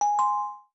alert.reversed.wav